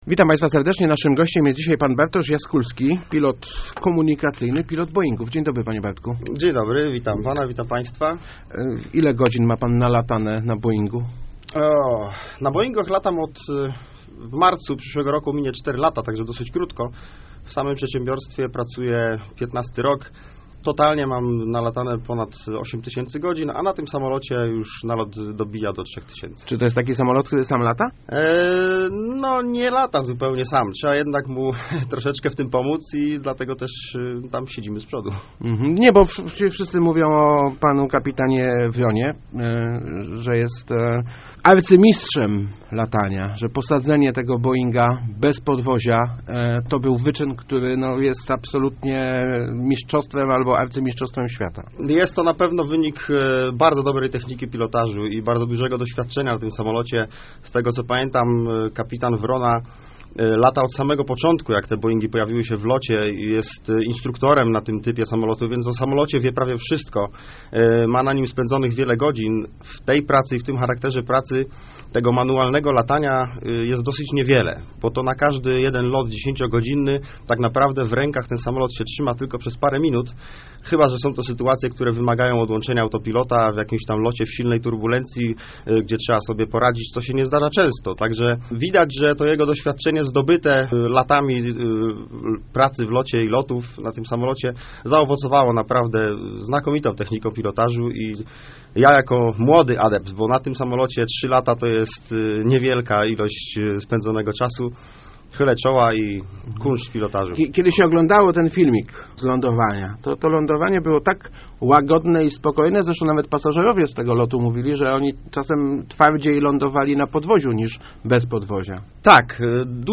dobrze ci ten wywiad lotniczy polecia�.